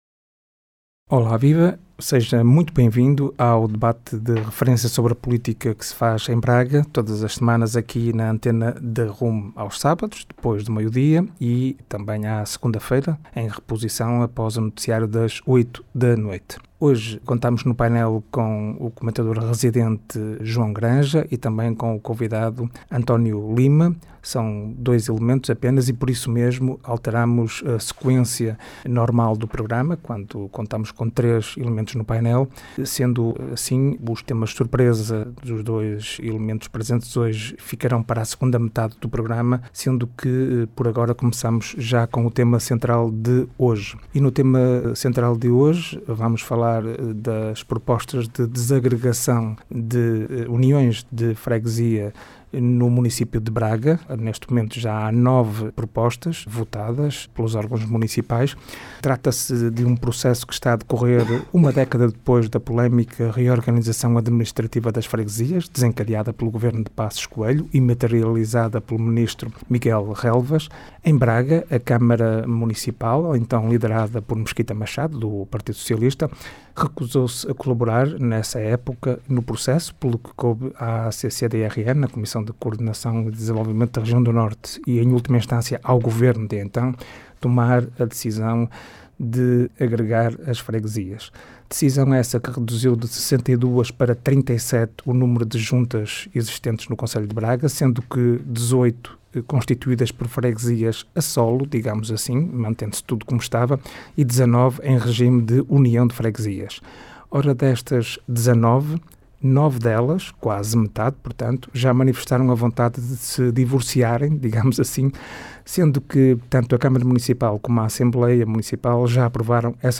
Programa de debate político